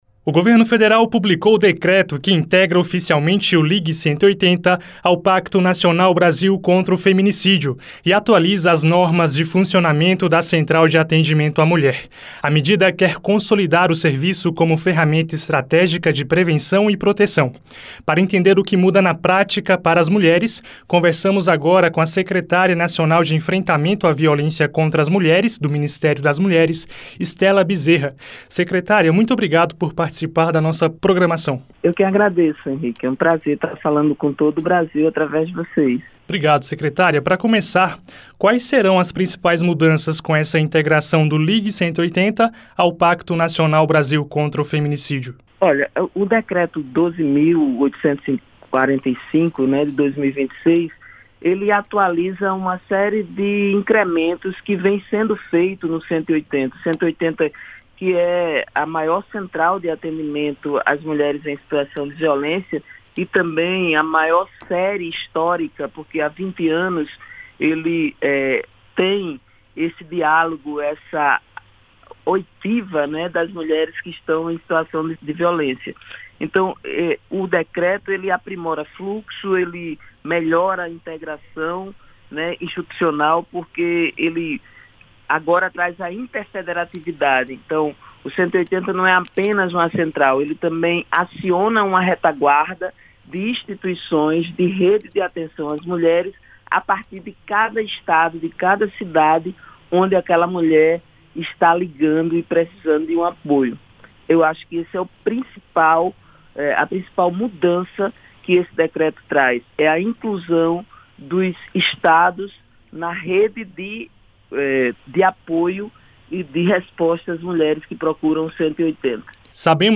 Para entender o que muda na prática para as mulheres, conversamos agora com a Secretária Nacional de Enfrentamento à Violência contra as Mulheres, do Ministério das Mulheres, Estela Bezerra.